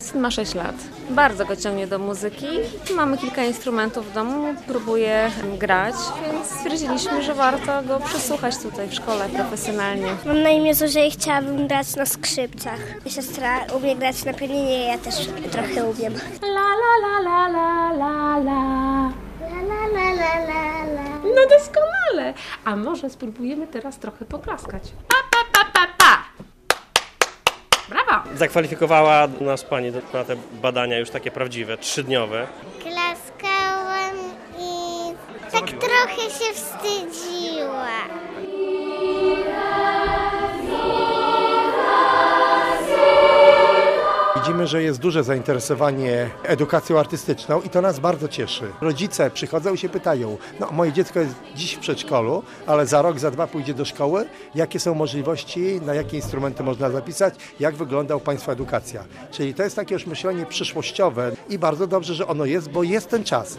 Wiosna u muzyków - relacja